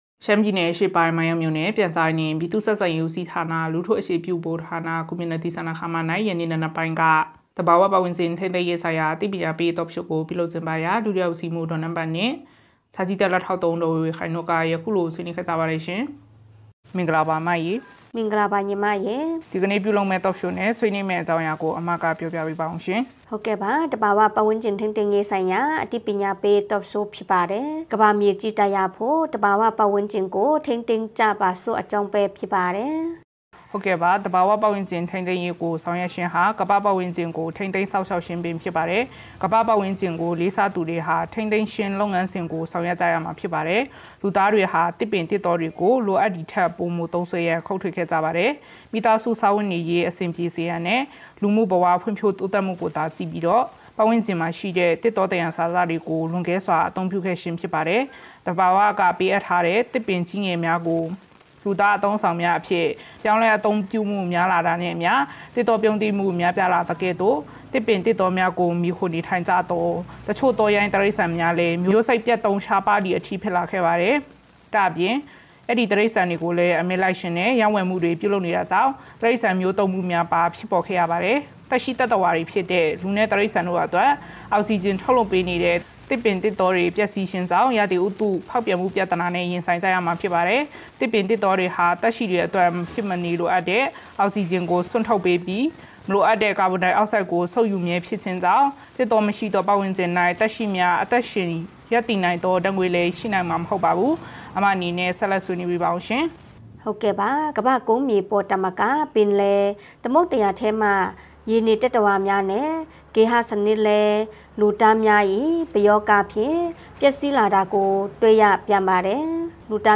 မိုင်းယောင်းမြို့၌ သဘာ၀ပတ်၀န်းကျင်ထိန်းသိမ်းရေးဆိုင်ရာ အသိပညာပေး Talk Show ပြုလုပ် မိုင်းယောင်း စက်တင်ဘာ ၁၁